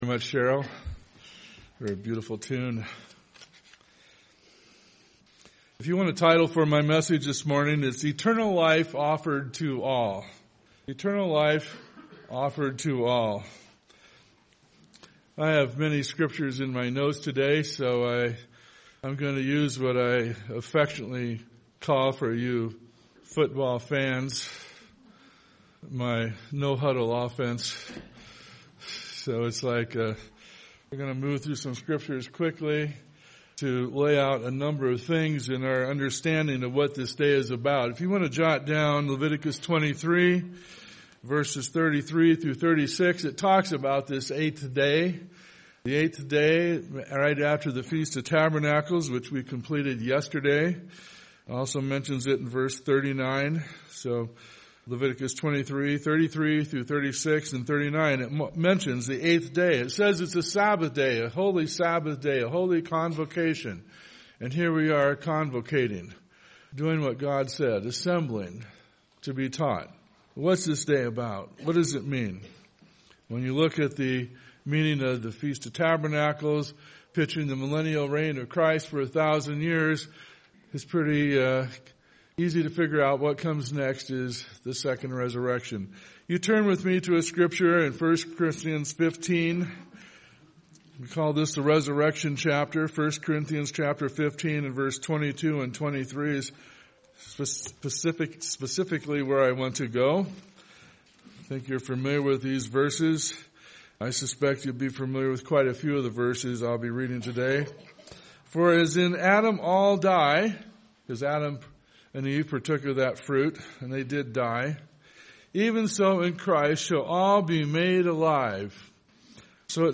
Sermons
Given in Bigfork, Montana